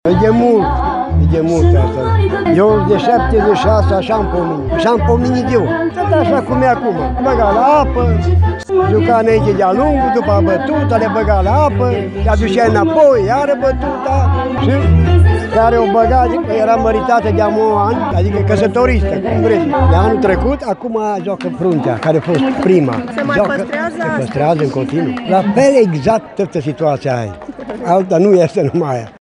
Bătrânii îşi amintesc de vremurile tinereţii lor, când şi-au văzut nevestele udate de bărbaţii din sat: